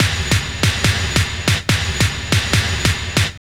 06.5 FILL.wav